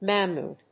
/ˈmæmud(米国英語), ˈmæmu:d(英国英語)/